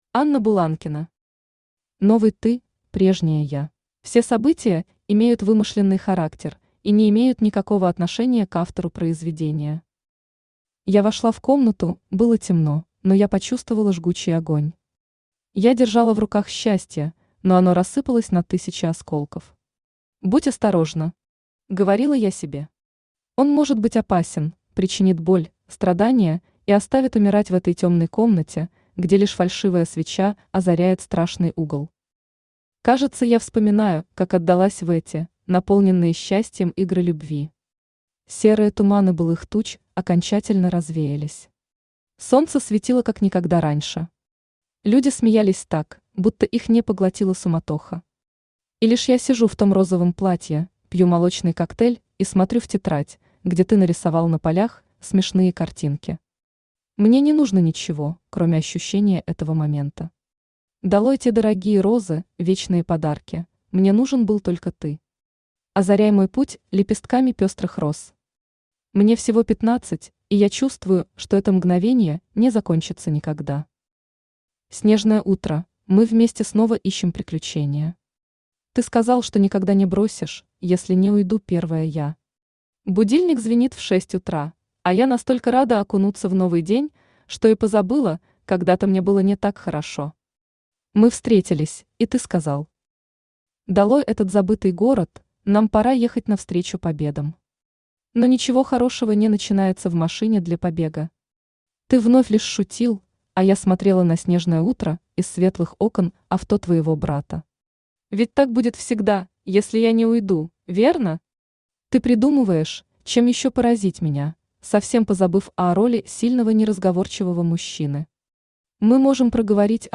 Аудиокнига Новый ты – прежняя я | Библиотека аудиокниг
Aудиокнига Новый ты – прежняя я Автор Анна Сергеевна Буланкина Читает аудиокнигу Авточтец ЛитРес.